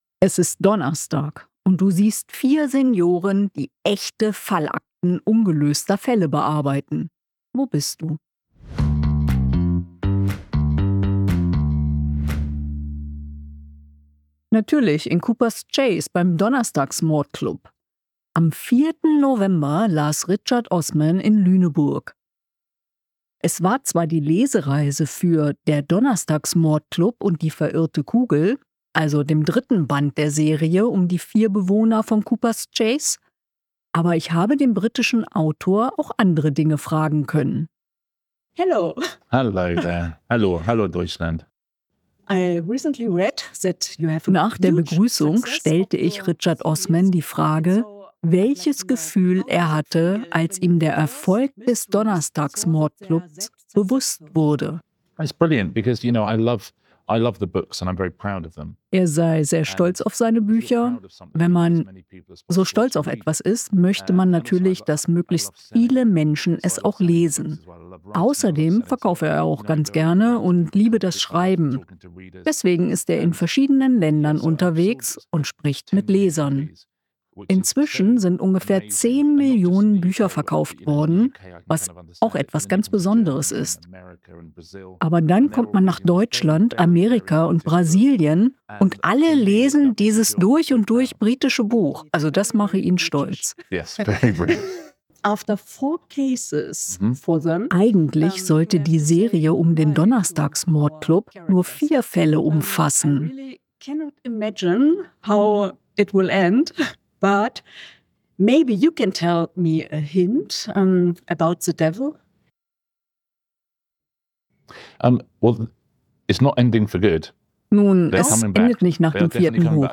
Wer noch keinem Hörbuch gelauscht hat, bekommt hier die Stimmen der Figuren präsentiert. Richard Osman erzählt außerdem ein bisschen was von seinen nächsten Plänen.